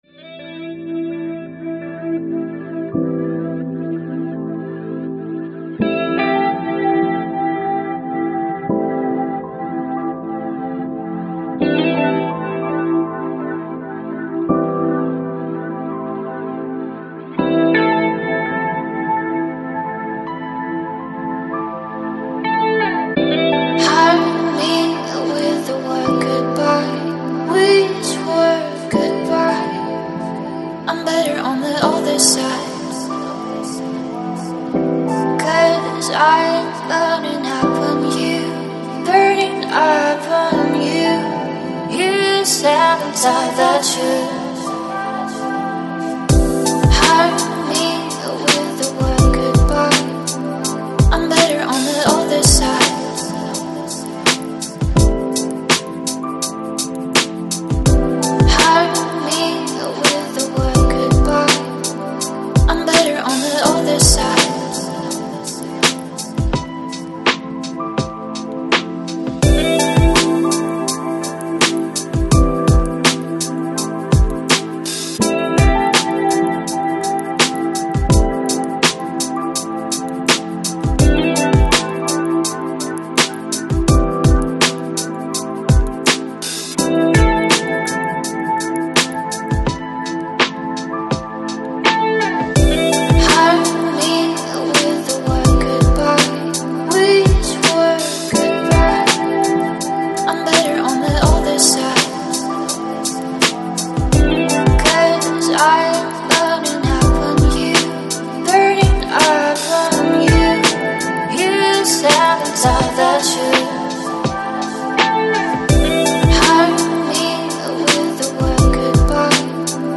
Electronic, Lounge, Chill Out, Downtempo, Balearic